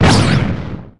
spike_ulti_explo_01.ogg